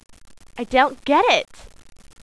RAVENCRI.WAV